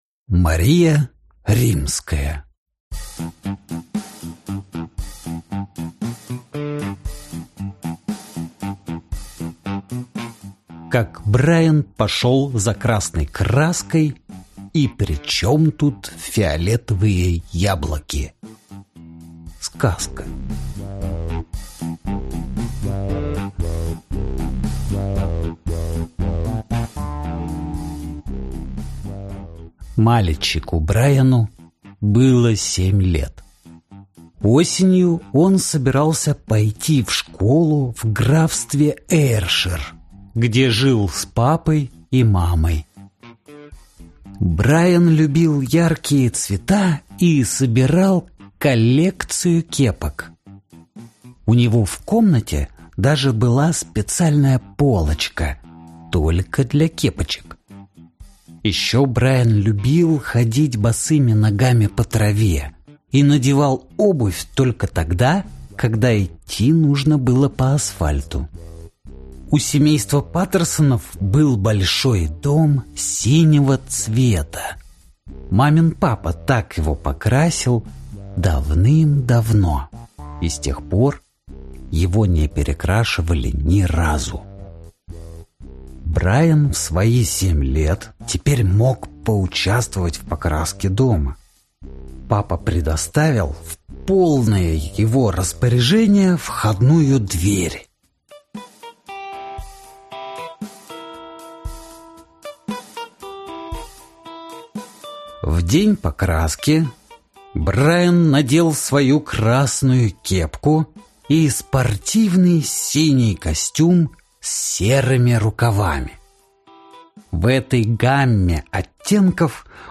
Аудиокнига Как Брайан пошёл за красной краской и при чём тут фиолетовые яблоки | Библиотека аудиокниг